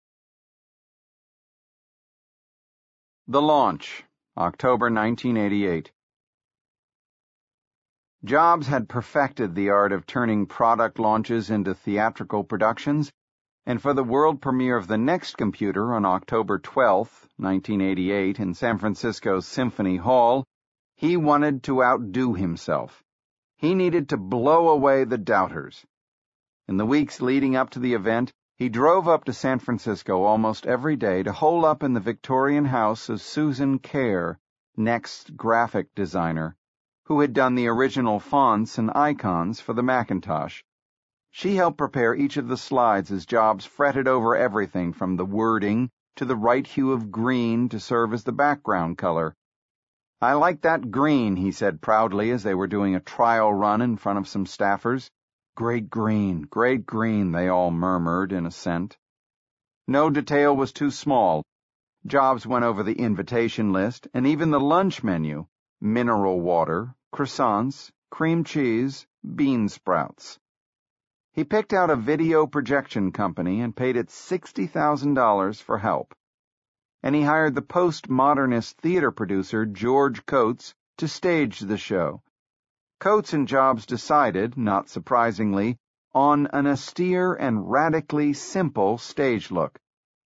在线英语听力室乔布斯传 第247期:NeXT发布会的听力文件下载,《乔布斯传》双语有声读物栏目，通过英语音频MP3和中英双语字幕，来帮助英语学习者提高英语听说能力。
本栏目纯正的英语发音，以及完整的传记内容，详细描述了乔布斯的一生，是学习英语的必备材料。